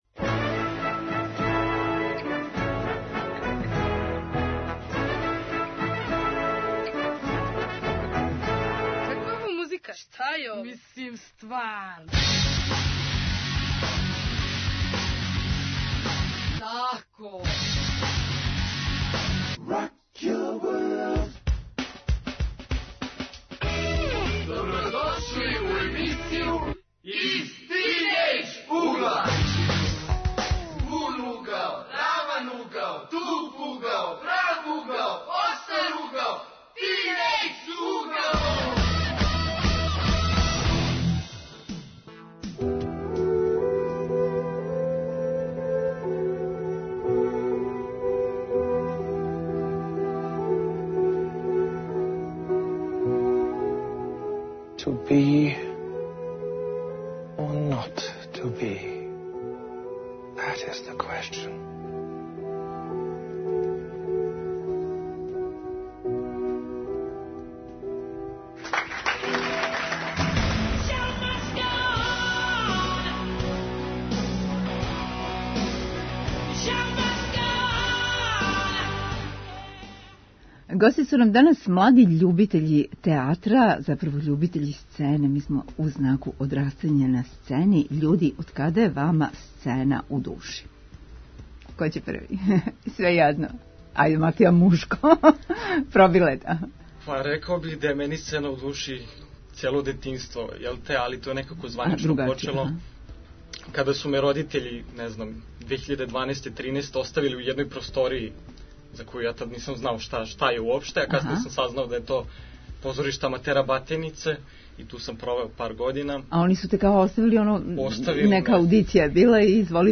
Гости у студију су млади из Дечје драмске групе РТС.